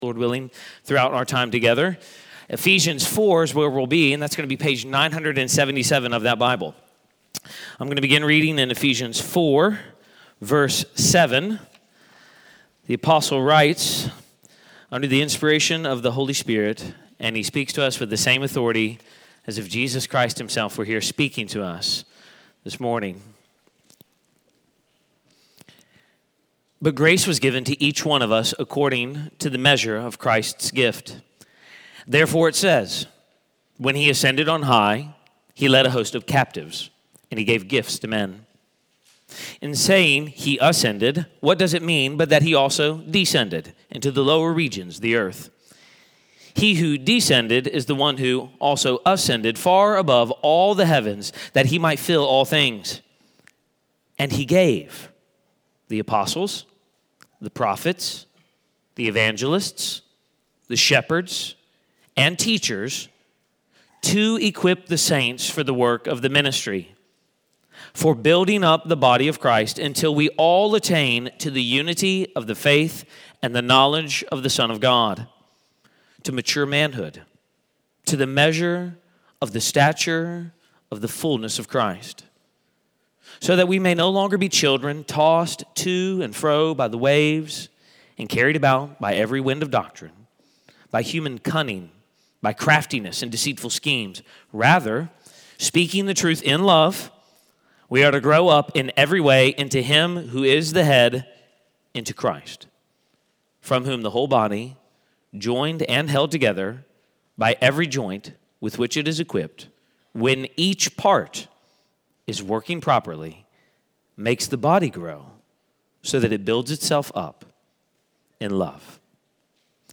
Sermon-112.mp3